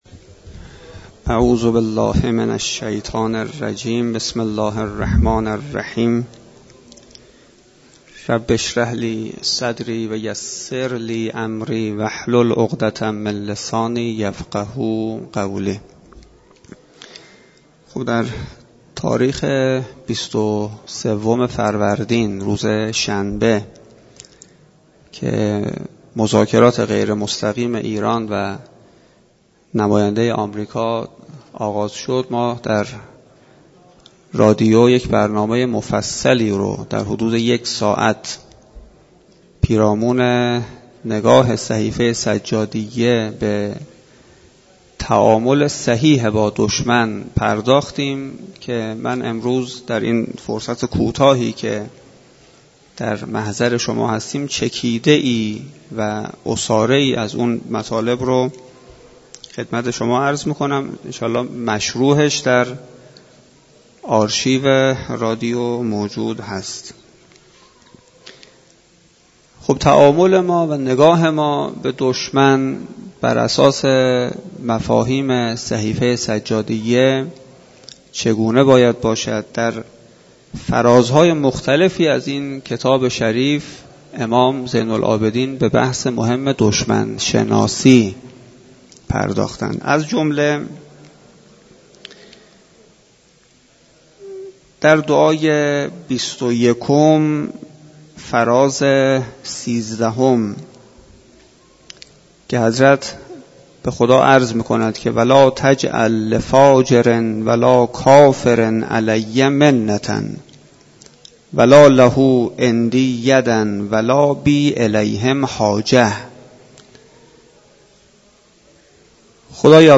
سخنرانی
در مسجد دانشگاه کاشان برگزار گردید.